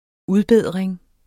Udtale [ ˈuðˌbεðˀɐ̯eŋ ]